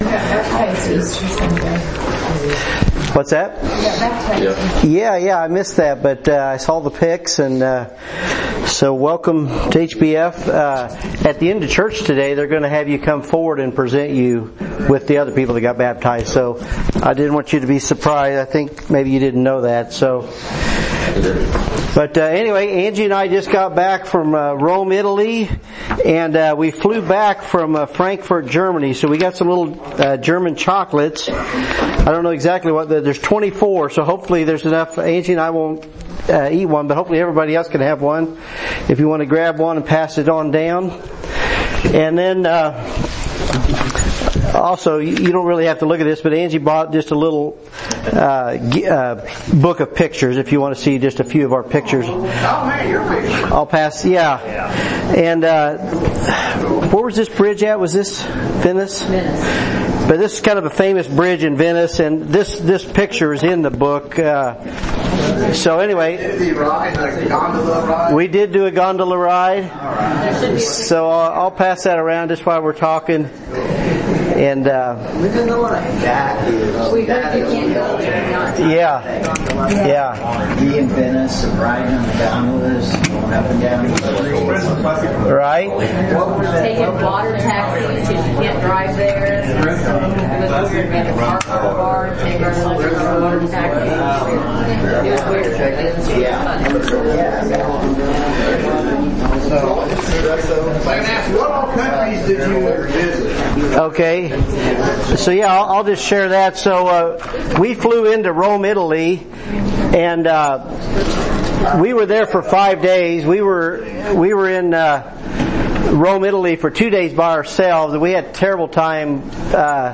The Book of Revelation Current Sermon Revelation lesson 17c